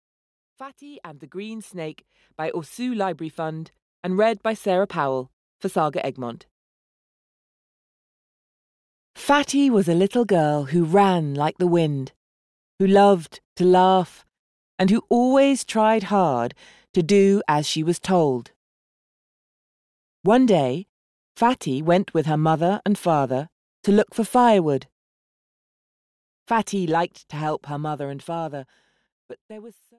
Fati and the Green Snake (EN) audiokniha
Ukázka z knihy